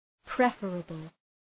Προφορά
{‘prefərəbəl}